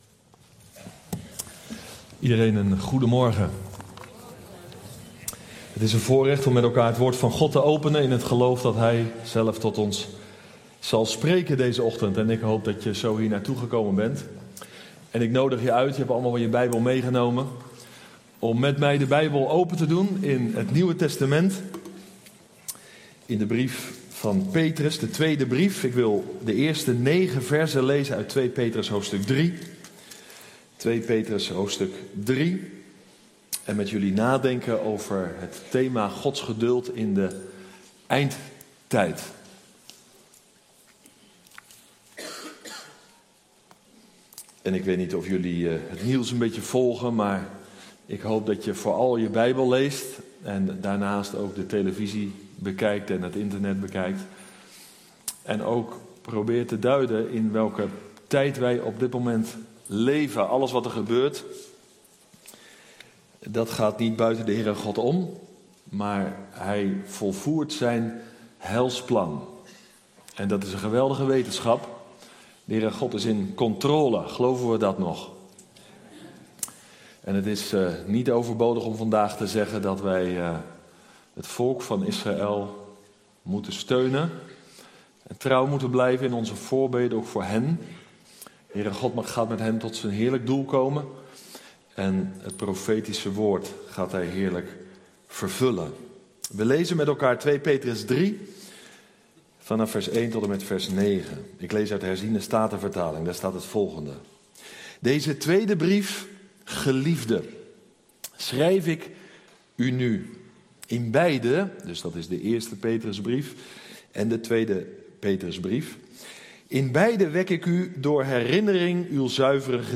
Passage: 2 Petrus 3:1-9 Soort Dienst: Reguliere dienst « Met Christus gestorven en opgewekt Hoe laat is het op de klok van de geschiedenis?